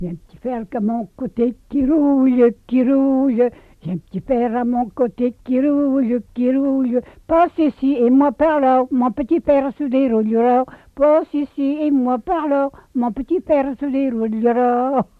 Couplets à danser
danse : branle
collecte en Vendée
Pièce musicale inédite